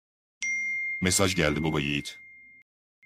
Pala Mesaj Bildirim Sesi
Kategori: Zil Sesleri
TikTok'ta viral olan "Mesaj geldi Baba yiğit" sesini ücretsiz indirerek telefonunuza farklı bir hava katın. Bedava olarak sunulan bu ses dosyası, her mesaj geldiğinde sizi Pala'nın etkileyici ses tonuyla uyandıracak.